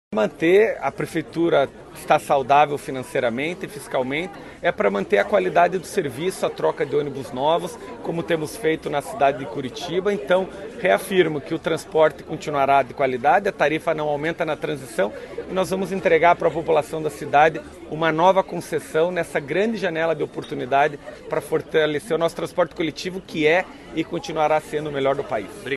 O prefeito de Curitiba, Eduardo Pimentel (PSD) reafirmou o compromisso fiscal da cidade e a manutenção do transporte coletivo “de qualidade” durante o processo de transição para a nova concessão. A afirmação foi feita nesta quarta (02), durante coletiva de imprensa.